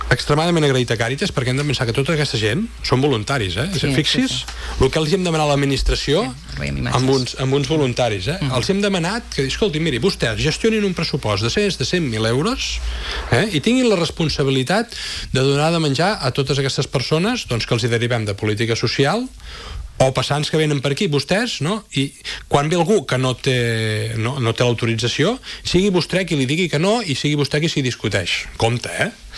N’ha parlat en una entrevista al matinal de Ràdio Calella Televisió, on ha detallat que el nou sistema s’implantarà a partir del 2026.